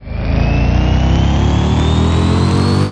apoweron.ogg